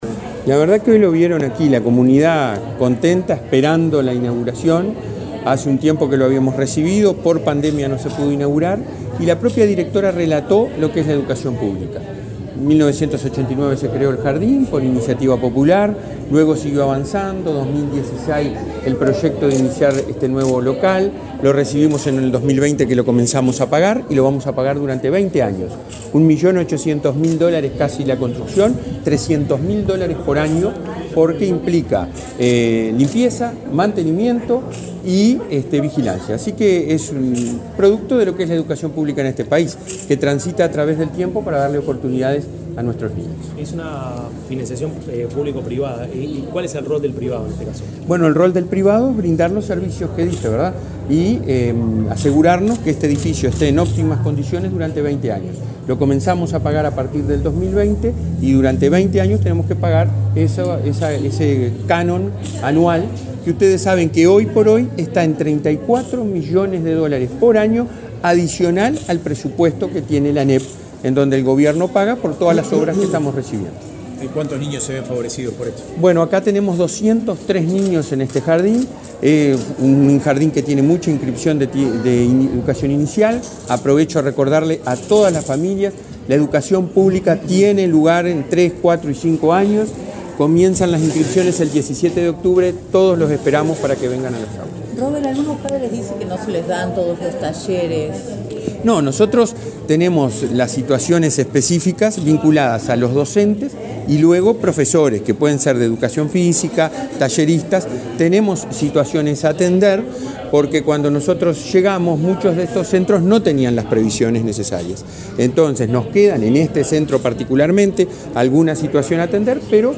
Declaraciones del presidente de ANEP, Robert Silva
Declaraciones del presidente de ANEP, Robert Silva 29/09/2023 Compartir Facebook X Copiar enlace WhatsApp LinkedIn El presidente de la Administración Nacional de Educación Pública (ANEP), Robert Silva, participó en la inauguración de dos jardines de infantes, este viernes 29 en Montevideo. En el centro n.° 306, Micaela Guyunusa, dialogó con la prensa.